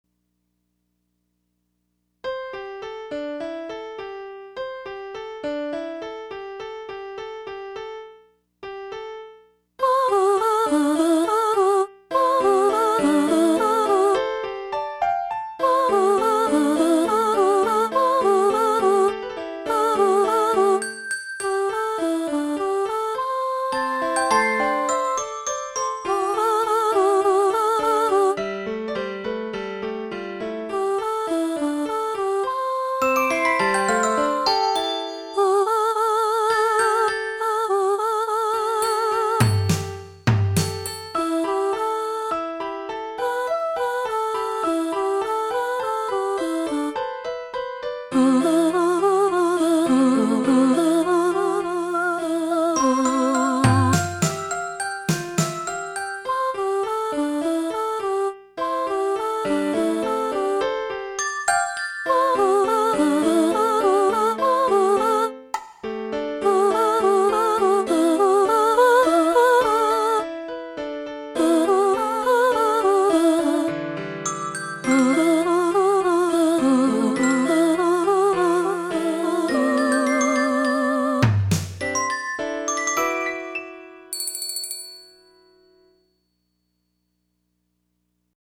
At present, the MP3 files were all generated by MIDI .
In vocal pieces, you will hear the vocal musical line but not the lyrics, which do appear in the score.
The entire cycle requires an adult choir, a children’s choir, a pianist, and two percussionists.